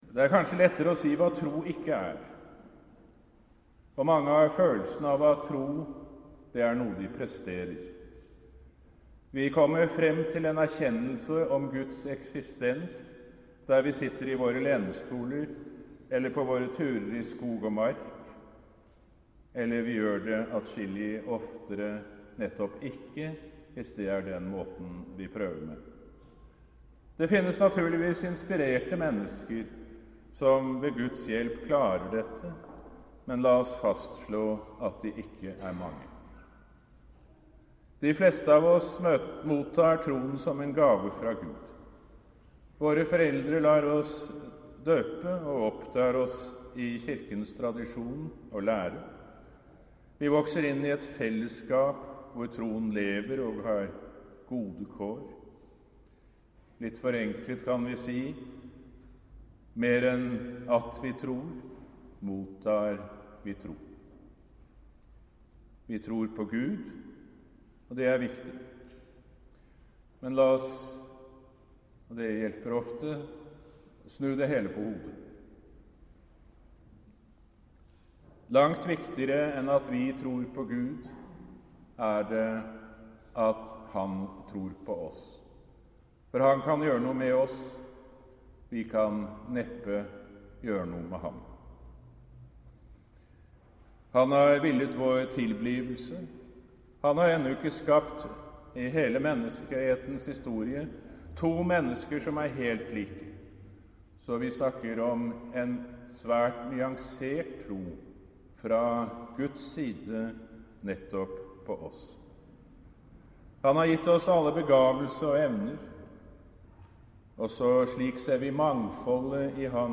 Miniforedrag holdt ved åpningen av Troens år i St. Olav domkirke 11. oktober, 2012.